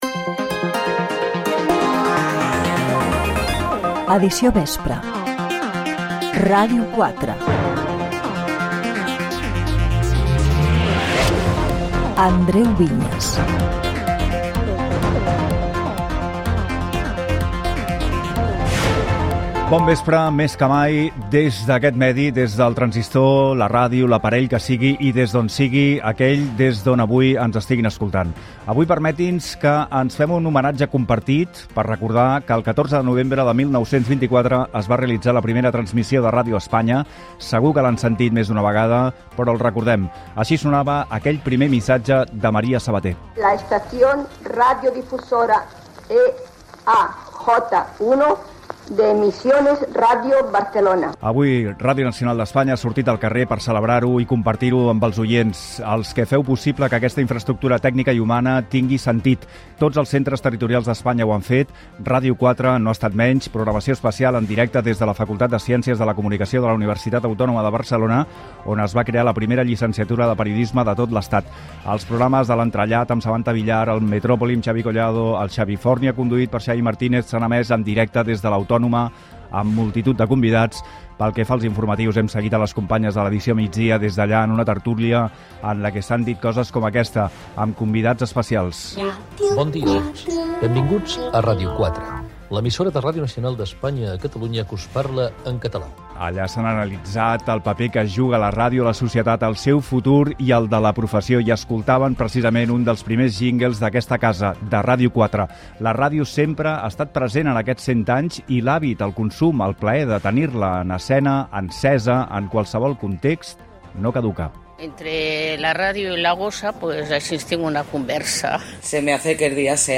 4affd5978f55e1551b70c536c5ac3b79ec924544.mp3 Títol Ràdio 4 Emissora Ràdio 4 Cadena RNE Titularitat Pública estatal Nom programa Edició vespre Descripció Careta del programa, programes especials de Ràdio 4 per celebrar els 100 anys de la ràdio a Catalunya, equip del programa, sumari informatiu, resum esportiu, informació del trànsit, el temps.
Informatiu